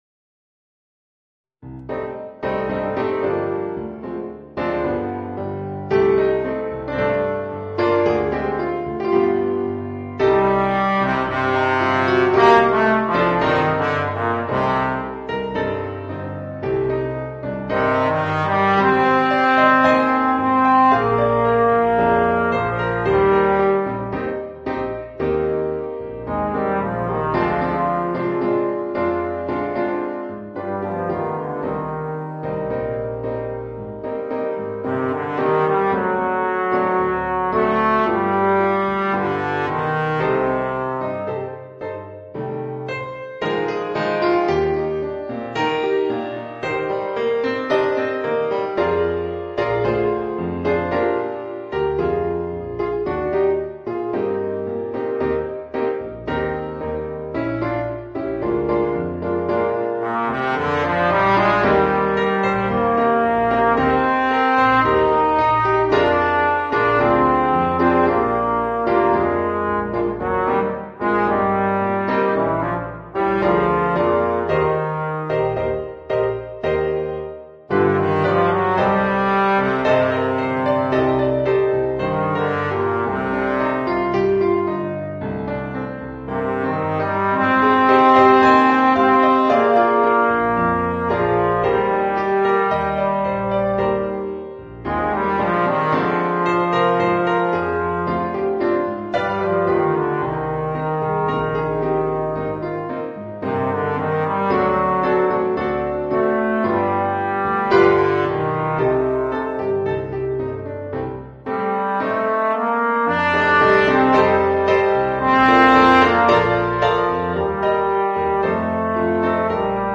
Trombone & Piano